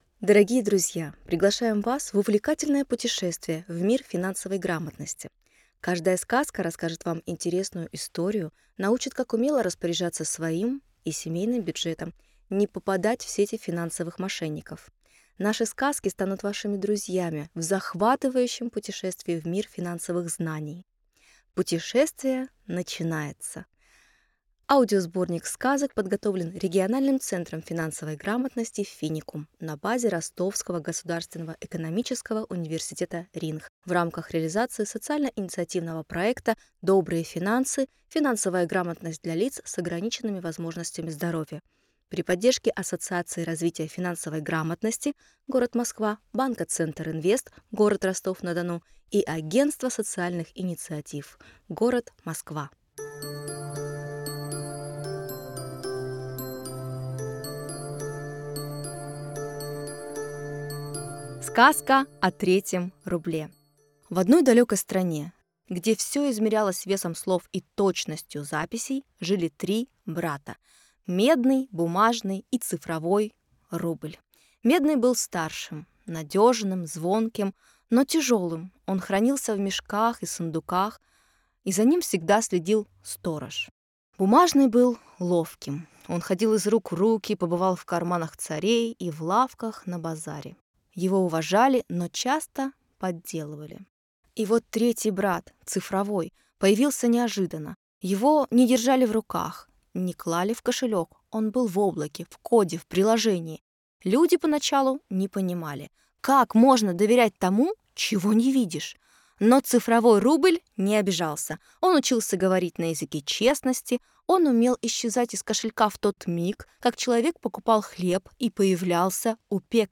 Аудиосказки по финансовой грамотности Добрые финансы